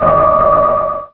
Cri de Gardevoir dans Pokémon Rubis et Saphir.